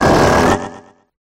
985cry.mp3